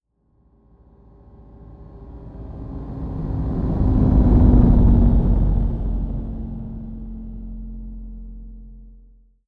decollage.wav